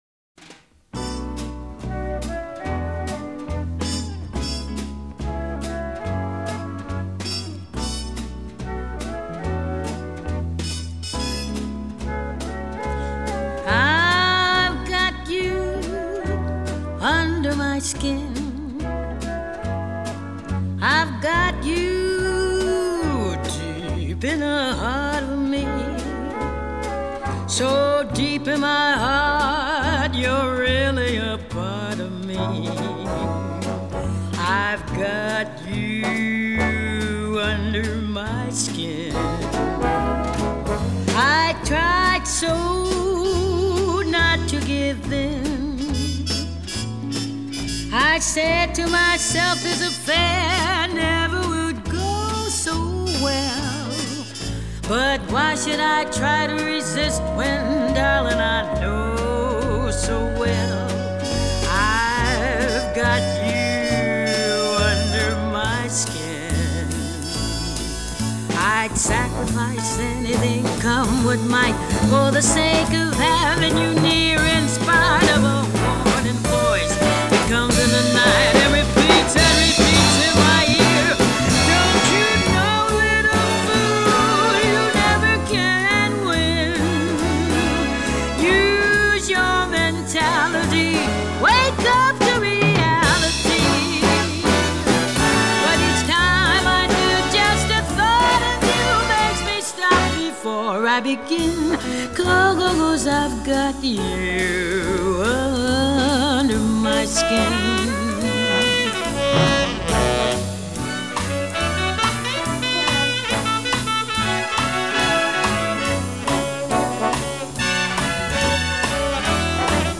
音樂類型：爵士樂